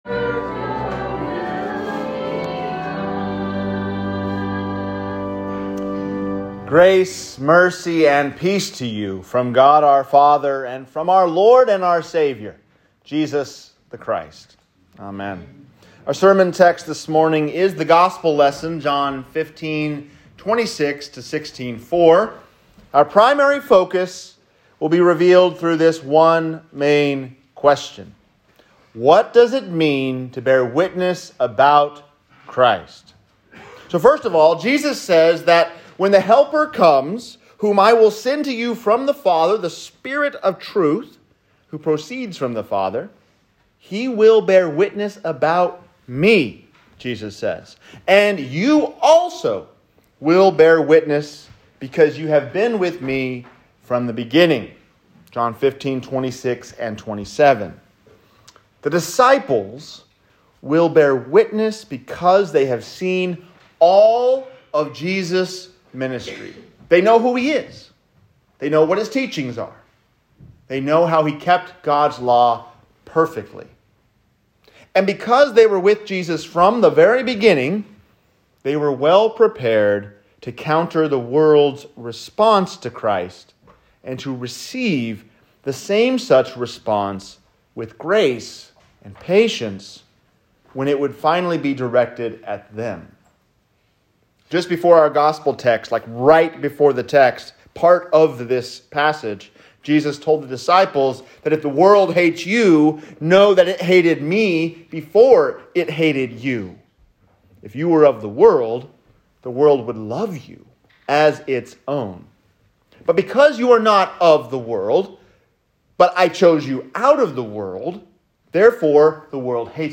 To Bear Witness about Christ | Sermon